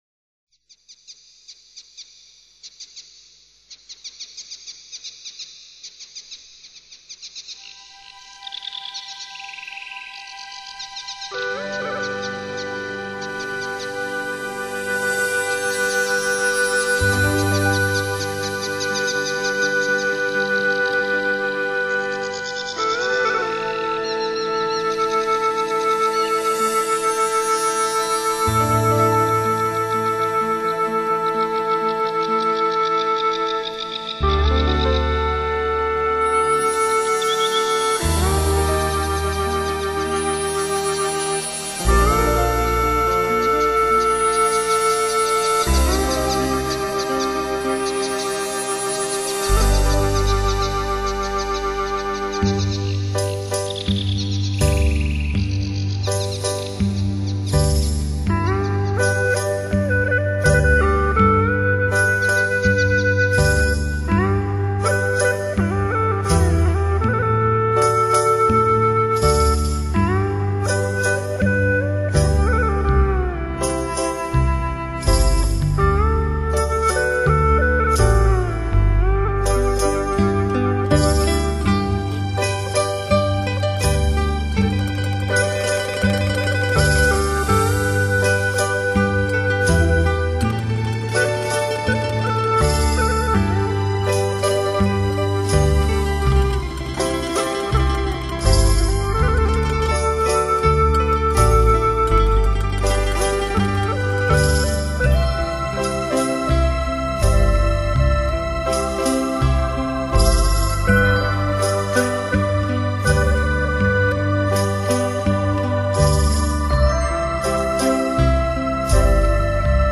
七首花樂精選，放慢花朵的視覺快板，讓花樂成為最自然的生活節拍。